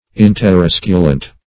Search Result for " interosculant" : The Collaborative International Dictionary of English v.0.48: Interosculant \In`ter*os"cu*lant\, a. 1. Mutually touching or intersecting; as, interosculant circles.